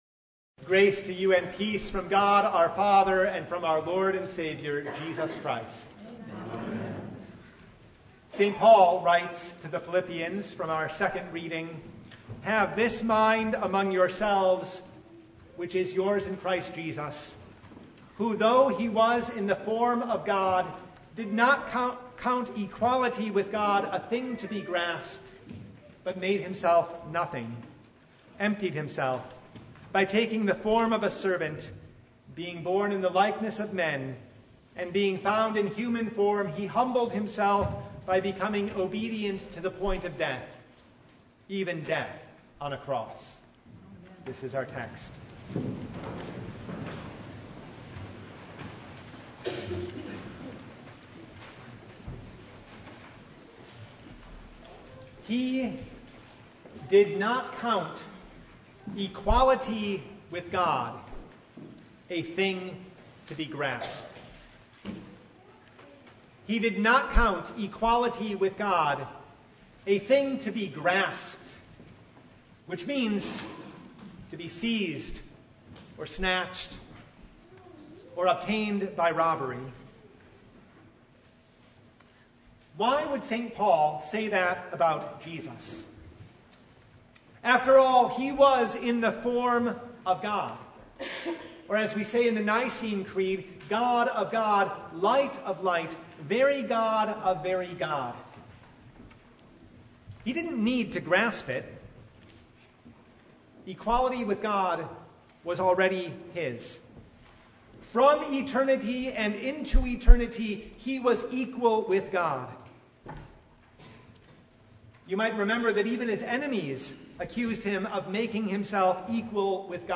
Service Type: Palm Sunday